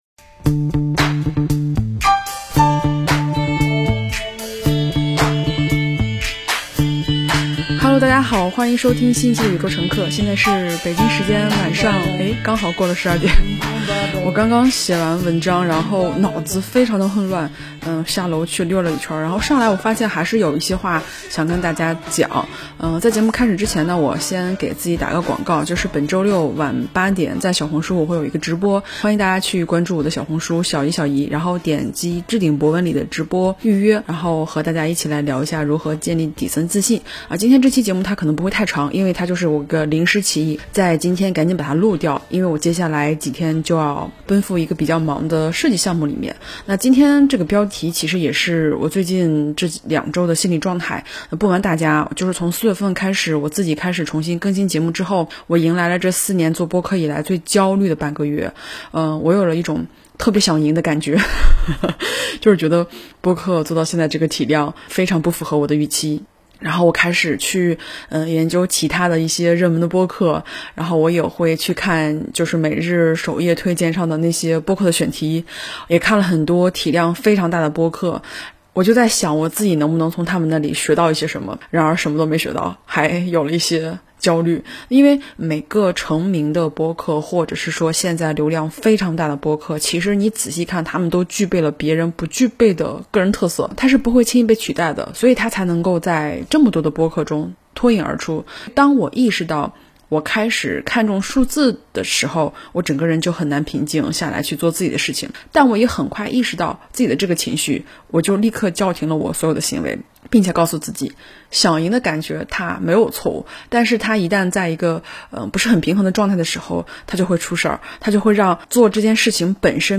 半夜来了灵感，solo 一下！